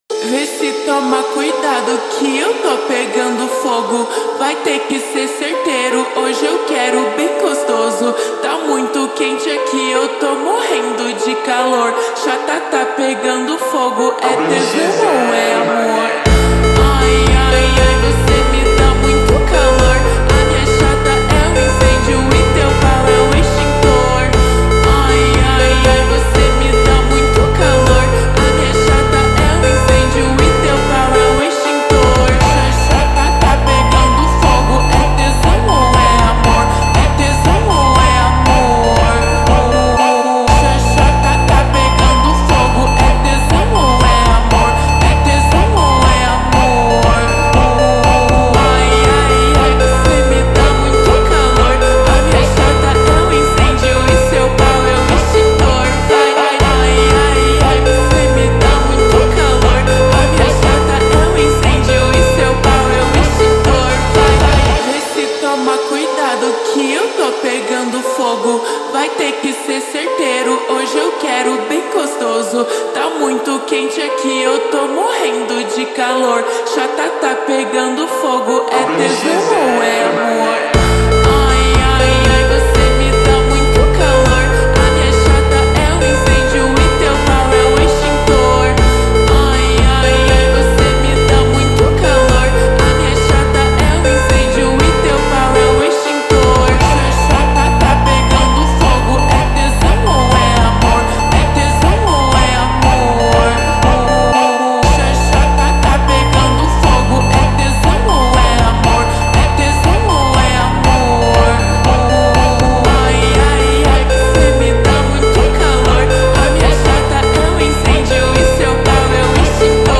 در نسخه slowed reverb
فانک
ماشینی
باشگاهی